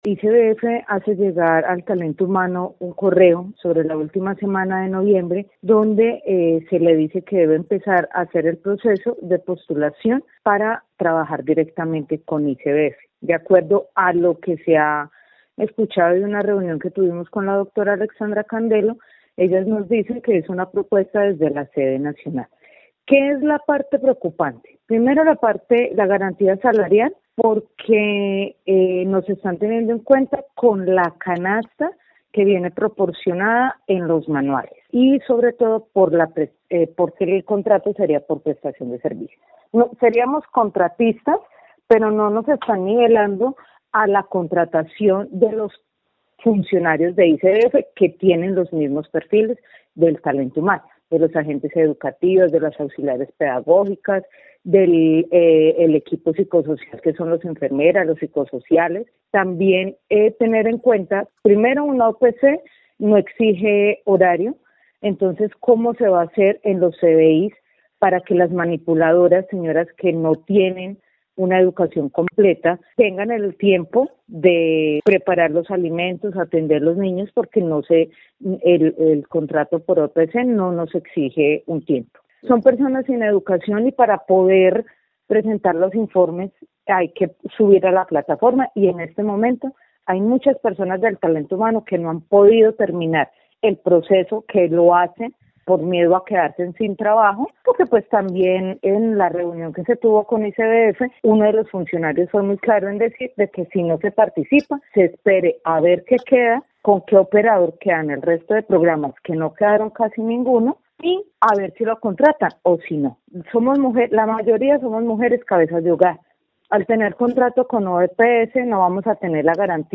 Declaraciones de trabajadora del ICBF